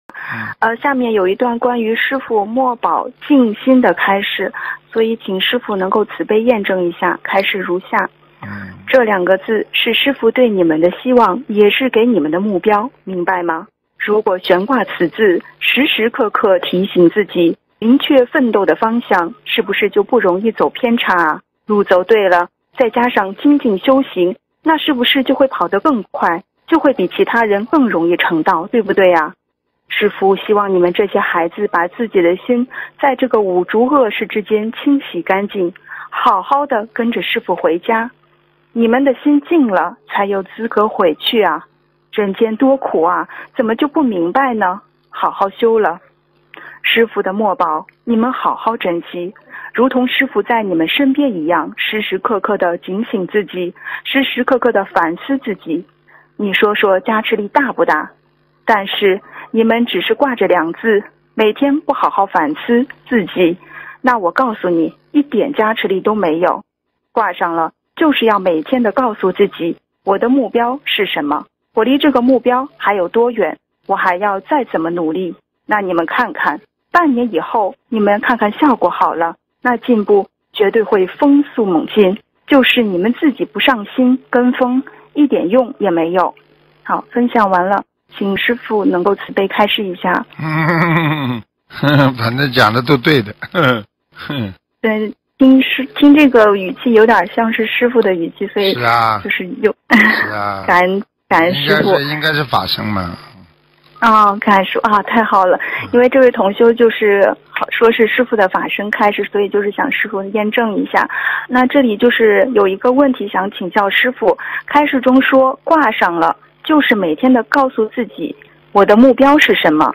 音频：关于师父墨宝净心的开示！问答2020年1月17日！